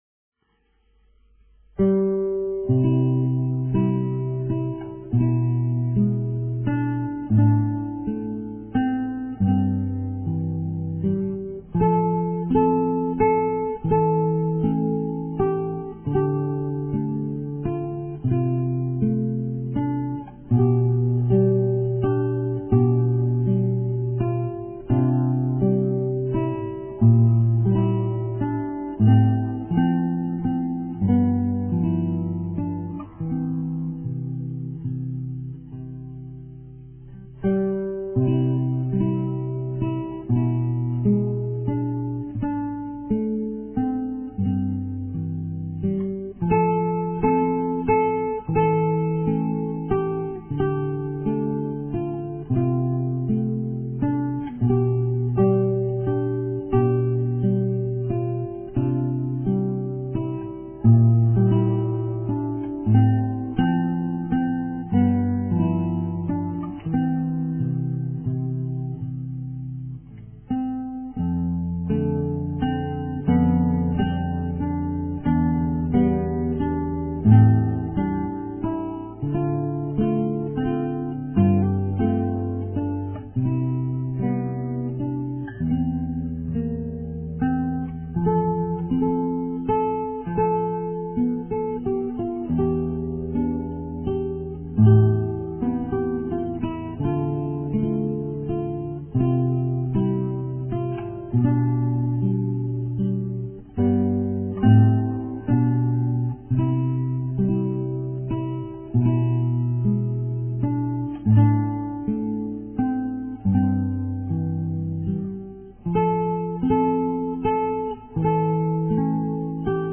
Keep every bass note as long as three beats on every bar.